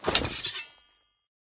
draw1.ogg